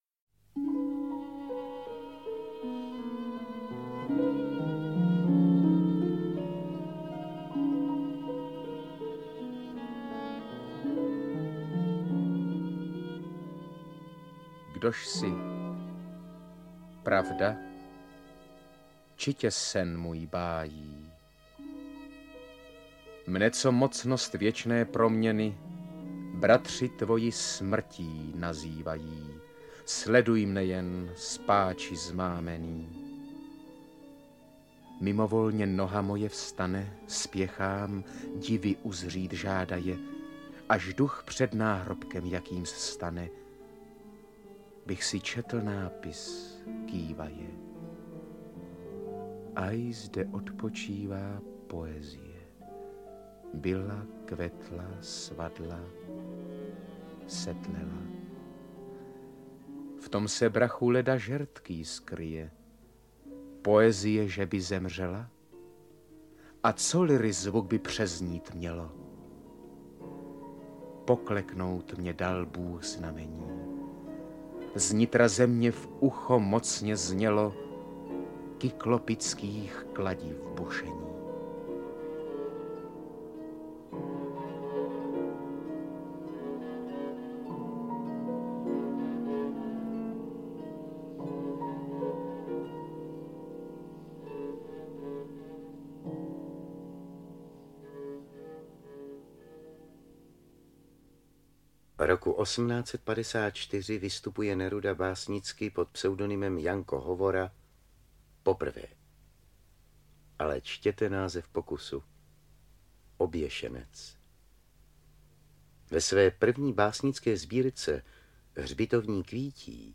Román lásky a cti. Dramatická montáž o Janu Nerudovi a Karolíně Světlé - Jindřich Honzl - Audiokniha
• Čte: Jana Dítětová, Blanka Bohdanová, Luděk…